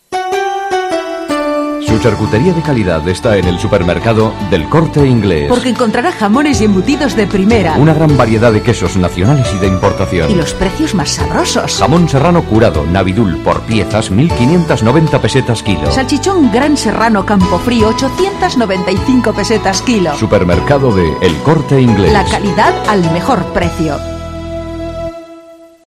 Anuncios sobre la fiesta de la moda, servicios de El Corte Inglés, supermercados, ropa de baño... narrados con voces sugerentes y aún en pesetas, que nos recuerdan cómo la herencia del pasado puede servirnos para alumbrar nuevas estrategias en el futuro.
Anuncio El Corte Inglés charcutería